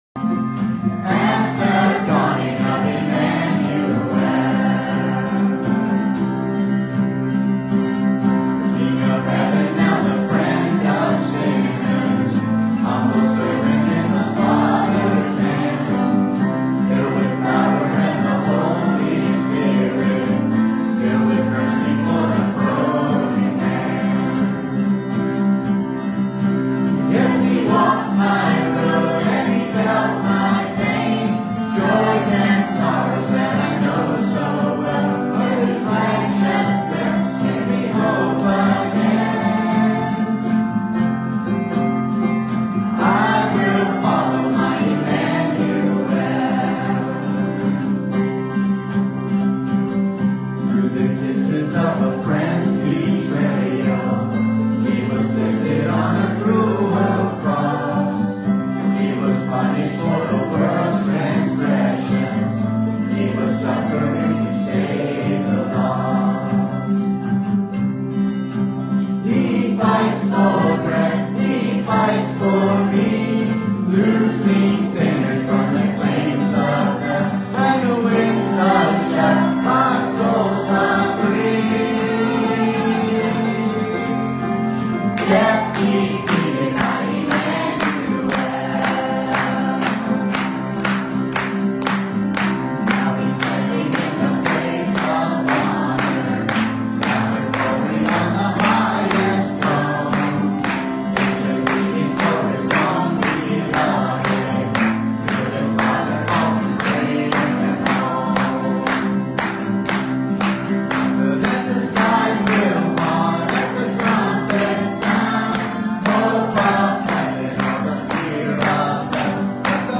Romans Passage: Romans 8:28 Service Type: Sunday Morning « All Things Work Together for Good The Miracle of Christmas